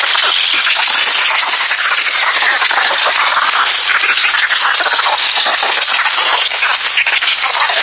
Звук радио , телевизор. Sound of radio, television set.
Звук радио " поиск в эфире" ( вариант 2).